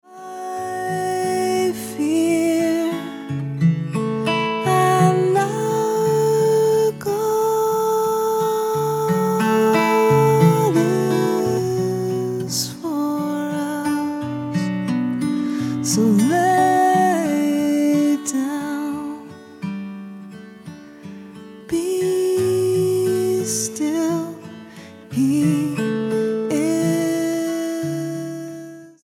Roots/Acoustic